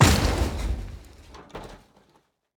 car-stone-impact-6.ogg